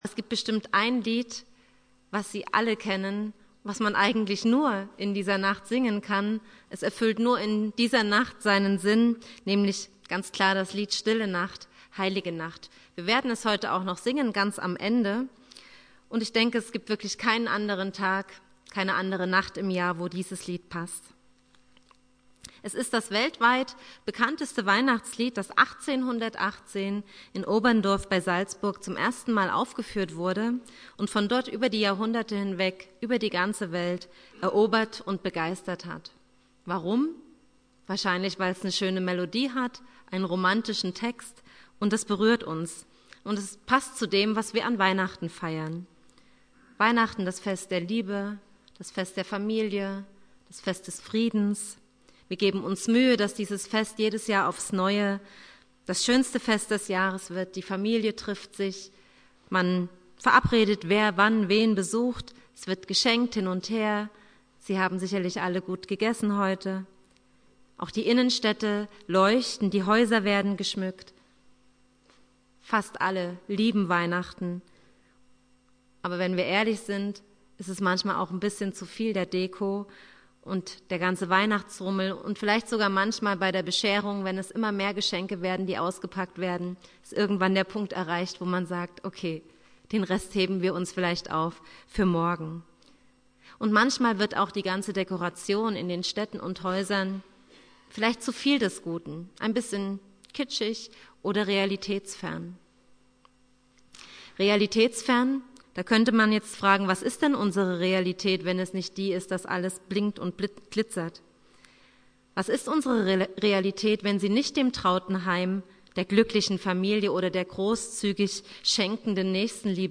Datum: So 24.12.2017, Heiligabend
Thema: "Stille Nacht" (Dialogpredigt um 23 Uhr)